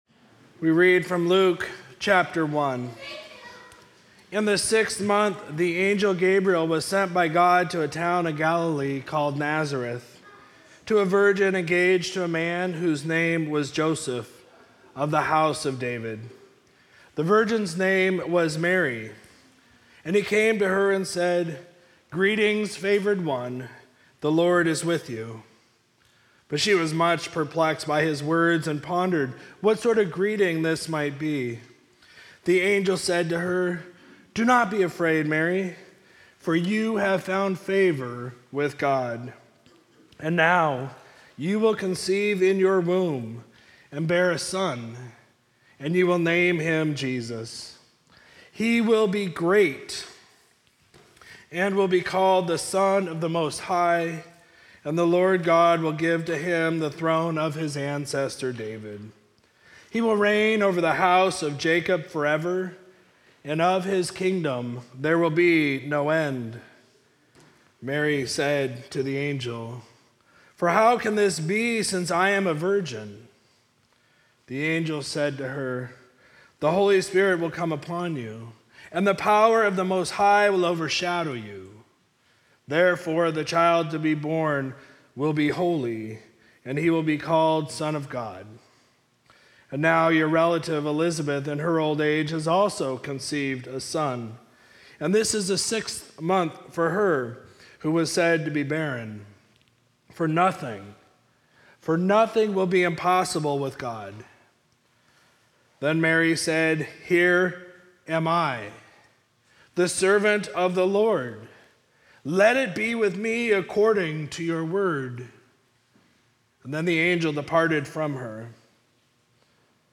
Sermon for Sunday, December 18, 2022
reader All Comers Choir and congregation, music